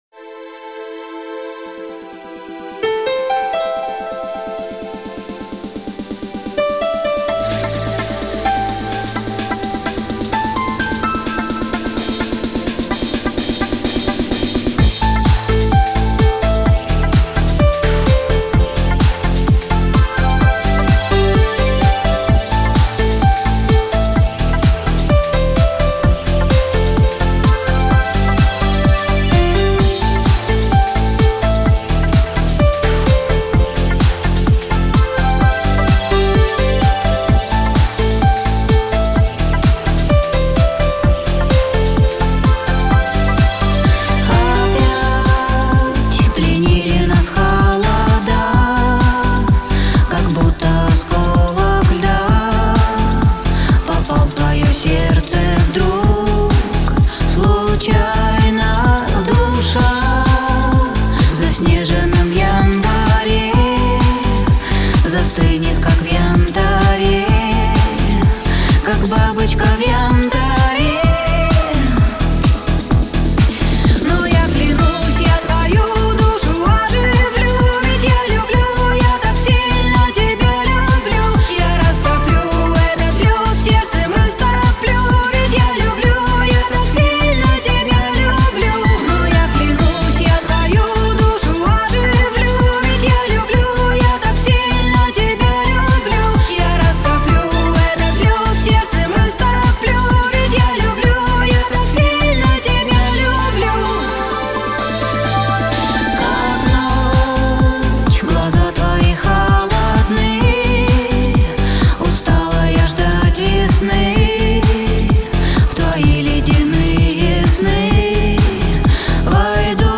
Ретро музыка